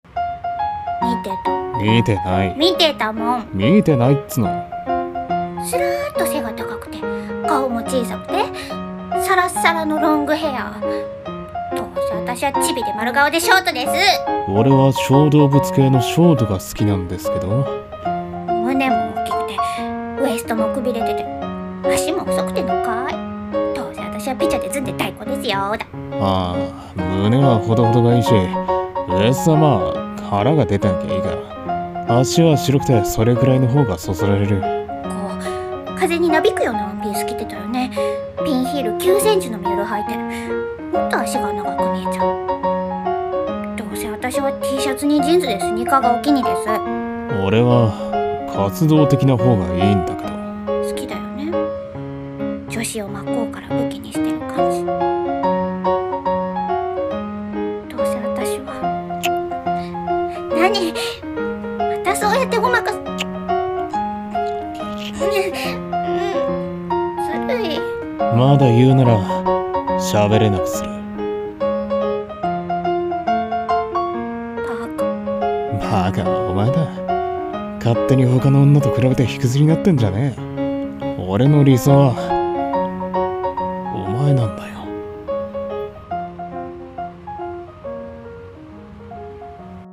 【声劇】お餅焼きましょ